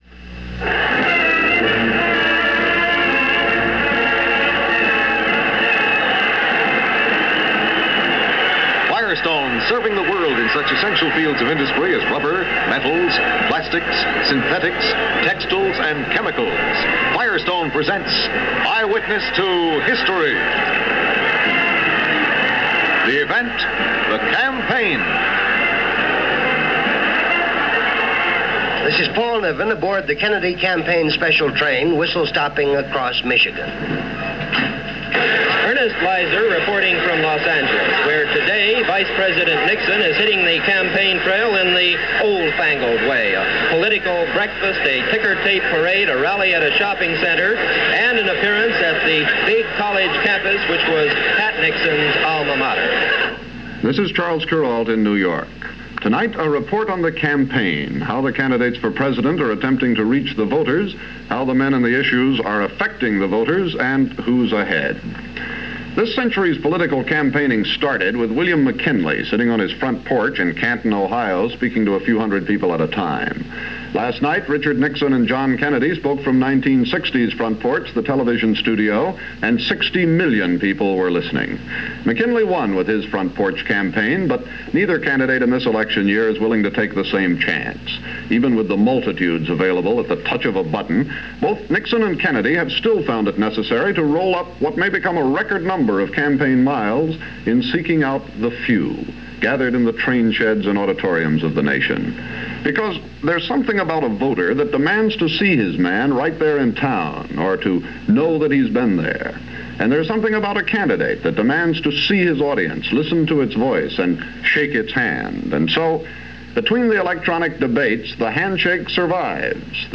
With the third Debate over the previous night, October 24th was the home stretch for the 1960 Presidential Campaign. As a recap of the race to the White House, CBS News ran a documentary on where the John F. Kennedy/Lyndon Johnson and Richard Nixon/Henry Cabot Lodge campaigns stood in the final days before the election.